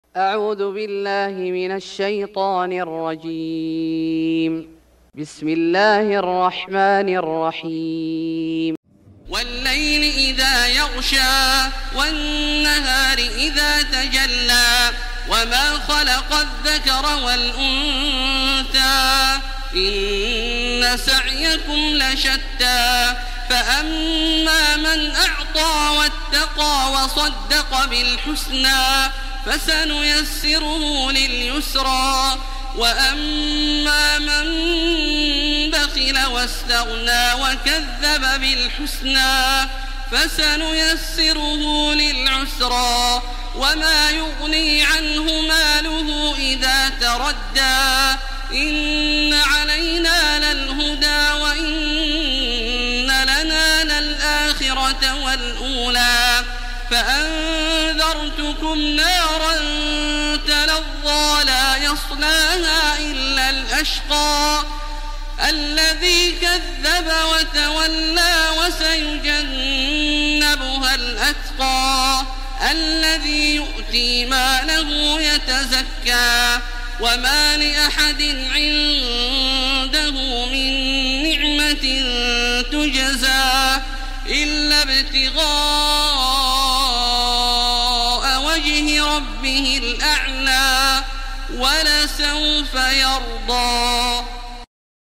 سورة الليل Surat Al-Lail > مصحف الشيخ عبدالله الجهني من الحرم المكي > المصحف - تلاوات الحرمين